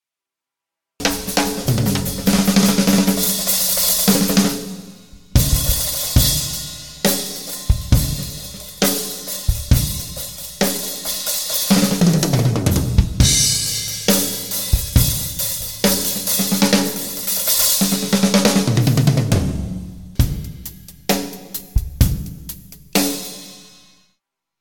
Percusión de altura indeterminada
Audio batería
Batería